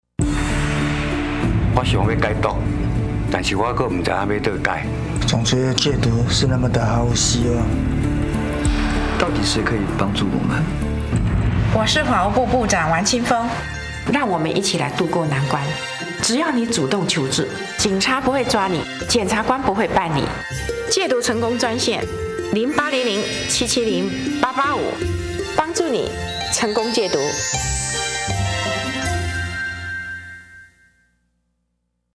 戒毒成功專線-廣播